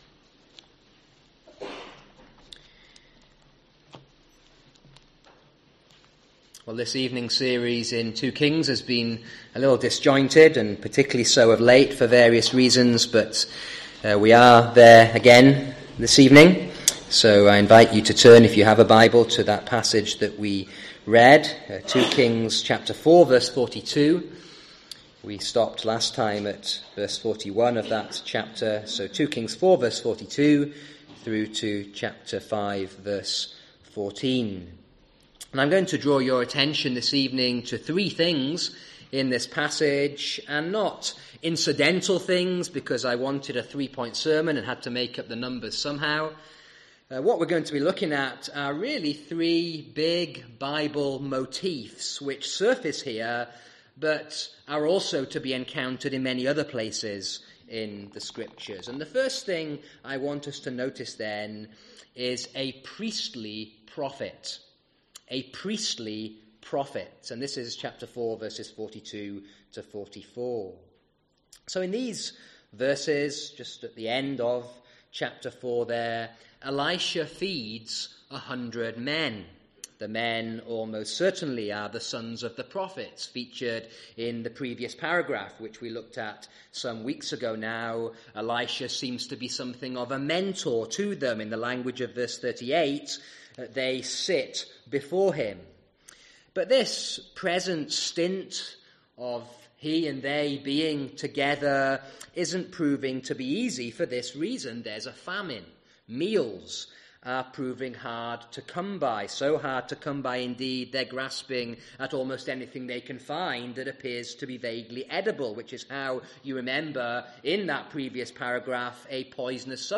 Sermons: reverse order of upload